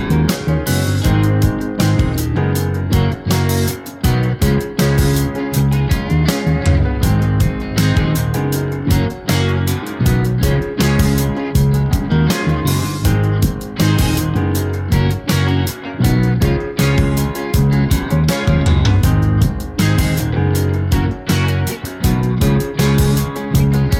One Semitone Down Pop